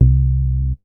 MoogBash.WAV